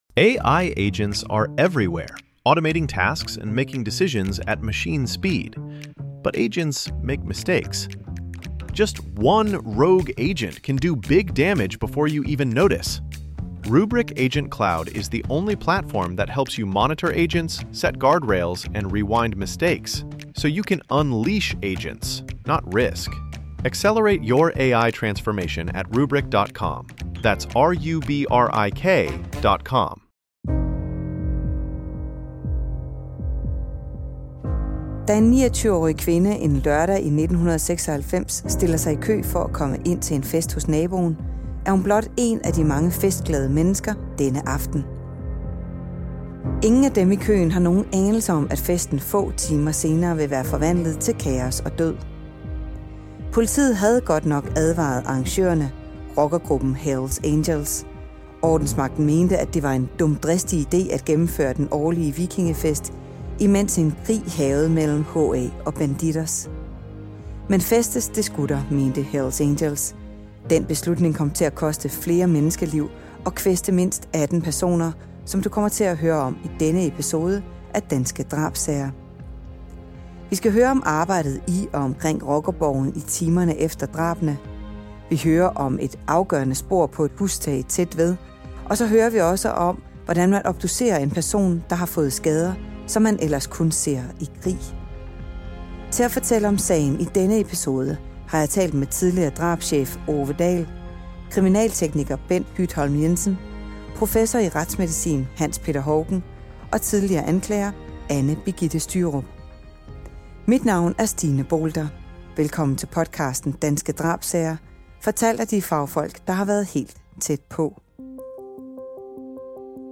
I denne udgave af Danske Drabssager hører du eksperterne fortælle om de hektiske timer og dage som fulgte efter en panserværnsraket havde hærget et festlokale på en rockerborg på Nørrebro i København. Du hører om et afgørende spor på et hustag tæt ved og om, hvordan man obducerer en person, som har fået skader, man ellers kun ser i krig.